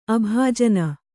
♪ abhājana